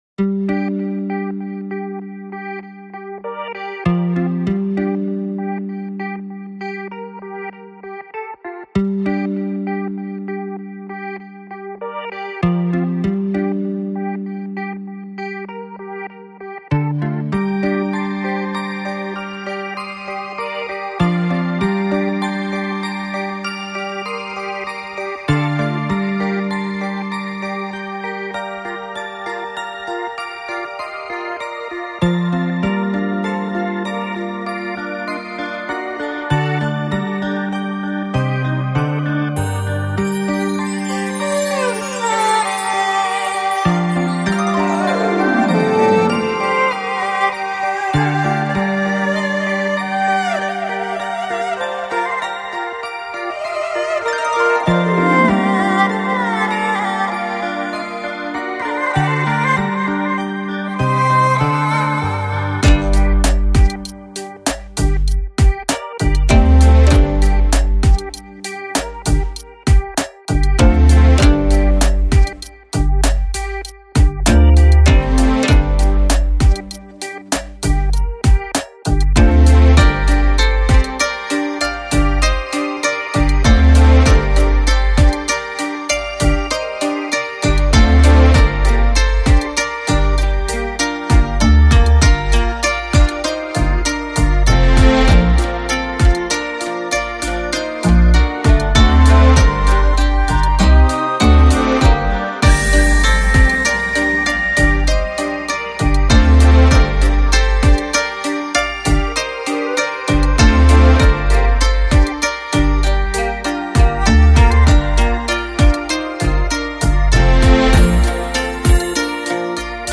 Жанр: Instrumental, Neo-Classical, New Age
一个由可爱的女孩组成的器乐乐队，以不同的风格和方向演奏器乐音乐。
小提琴和键盘、鼓、打击乐、吉他和手风琴、白俄罗斯琴和印度锡塔尔琴、中国胡卢西——乐器都是乐队在舞台上表演的音乐角色。